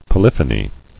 [po·lyph·o·ny]